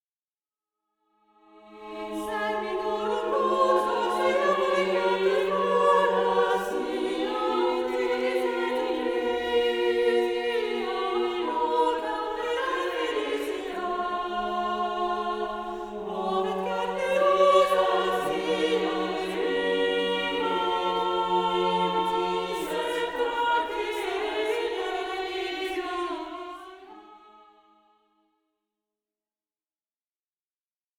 Motet